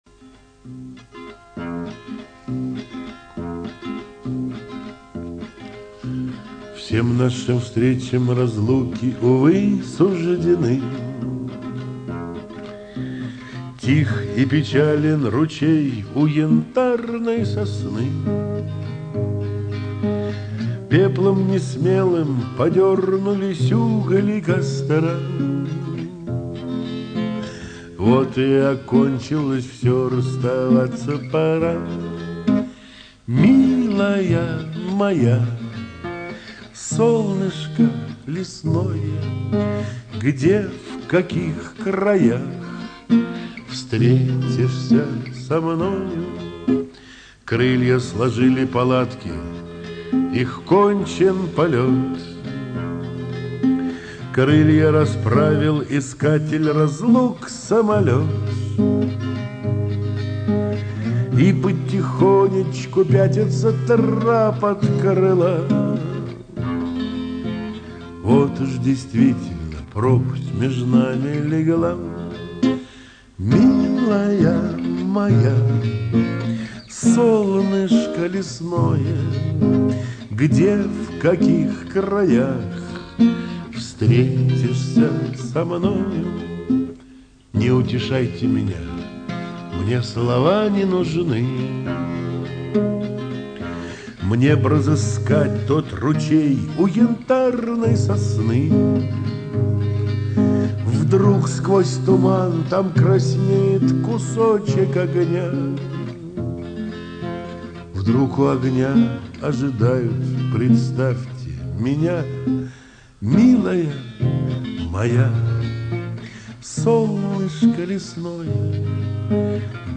Исполняет автор (скачать)